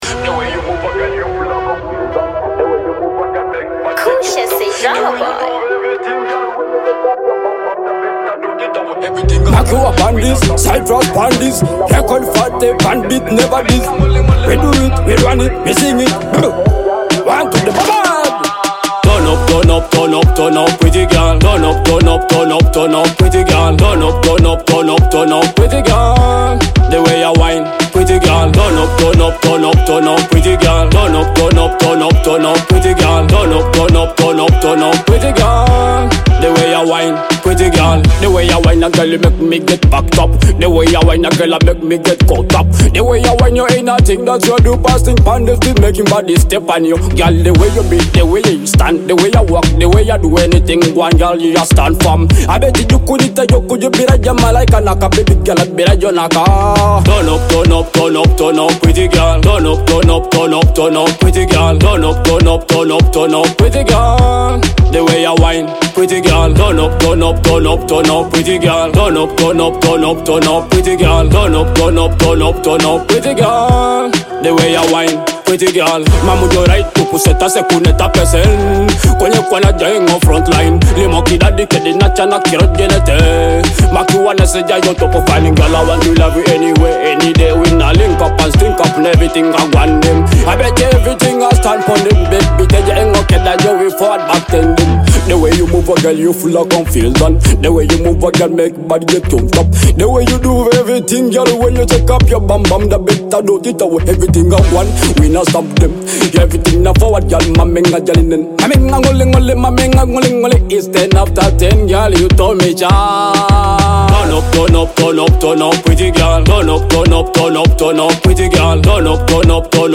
Afrobeat and dancehall influences